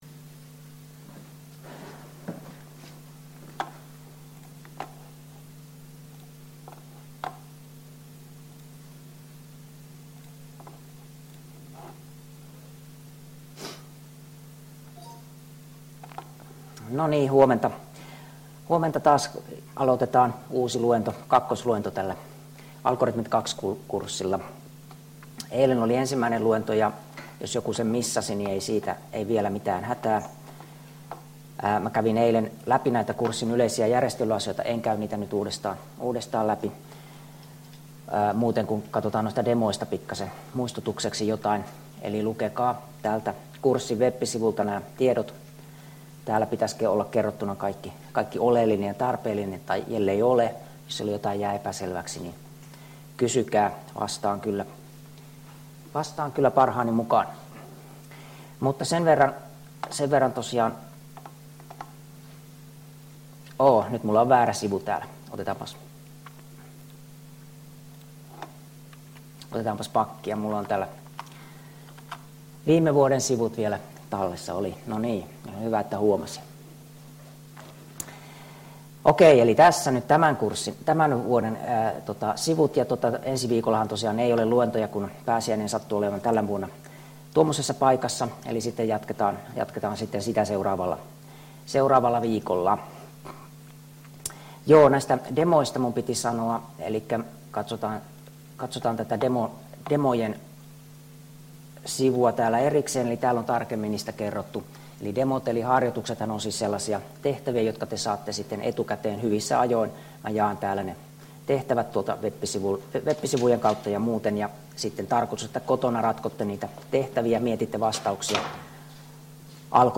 Luento 2 — Moniviestin